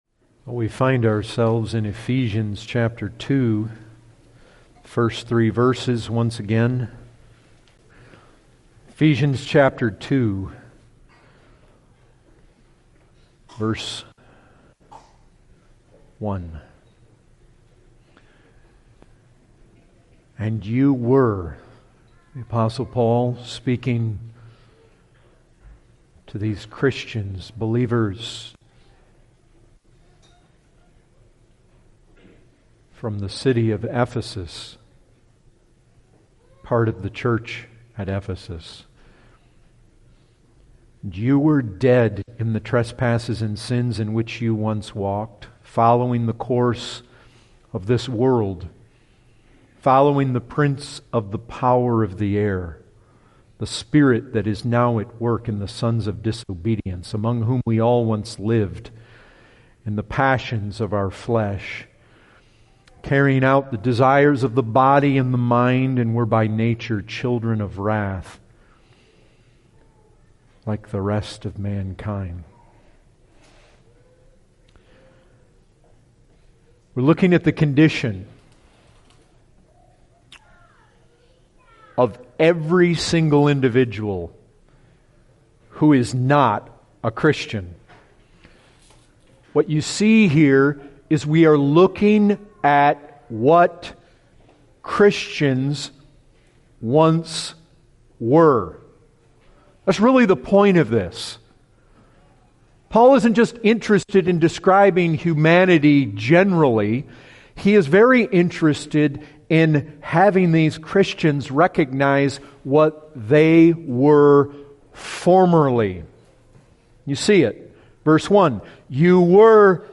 Category: Full Sermons